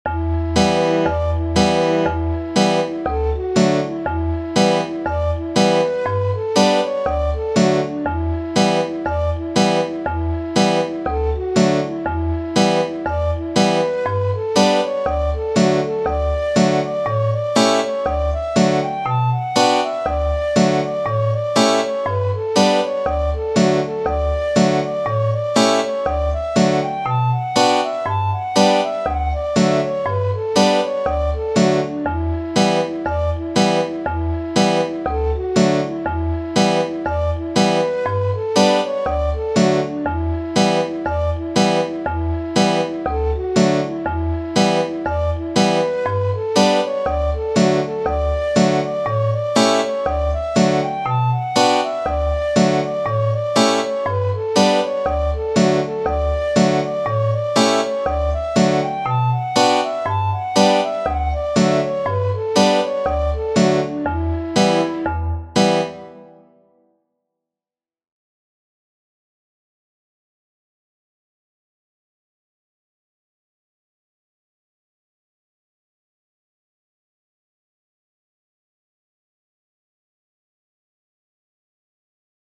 It is a tune I added to my repertoire as I promised, but I've recently been re-visiting some of my favourite fiddle tunes and creating (very simple) backing tracks with MuseScore.
The second .mp3 is the same, but with fiddle removed....
drowsy_maggie_ensemble-no-violin.mp3